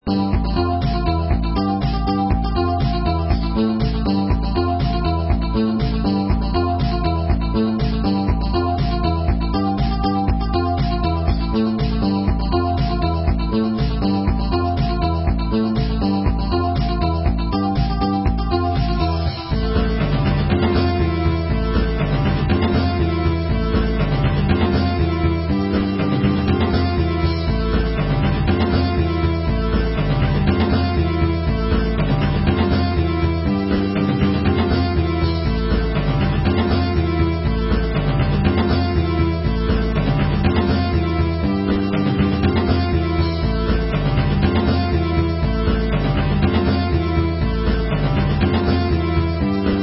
• Жанр: Танцевальная
Euro-pop / synth-rock. 2001.